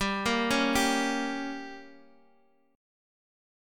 Gdim chord